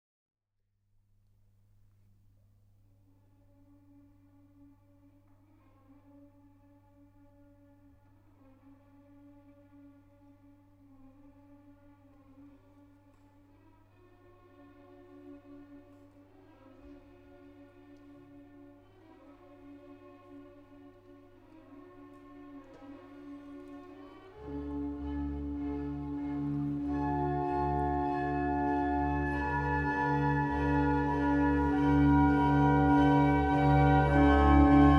Classical Orchestral
Жанр: Классика